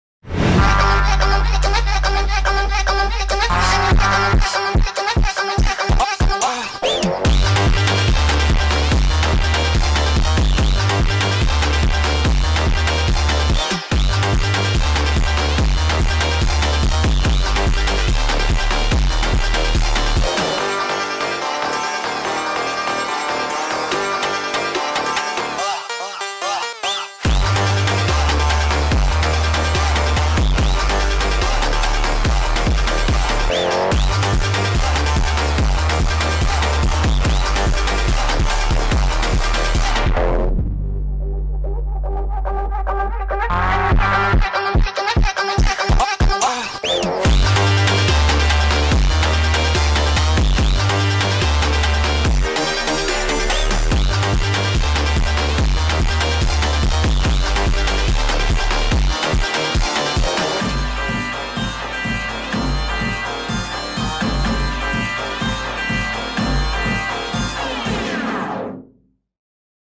فانک
ماشینی